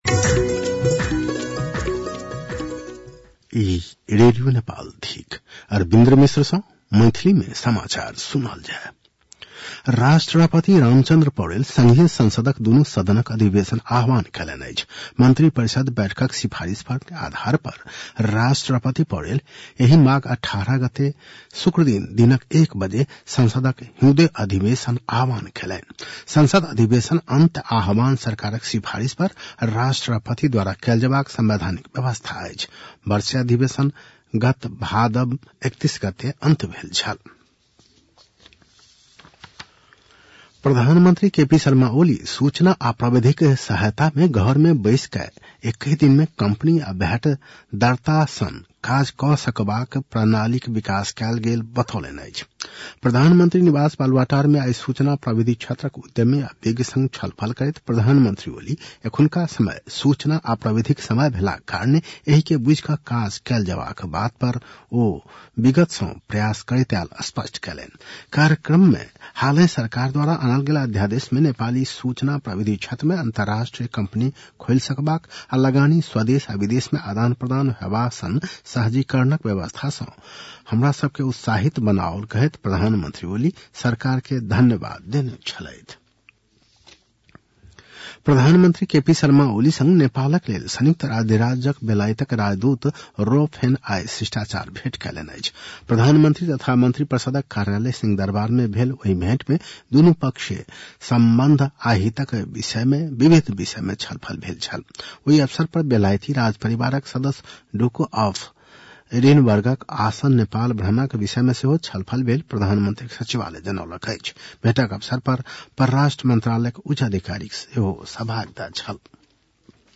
मैथिली भाषामा समाचार : ९ माघ , २०८१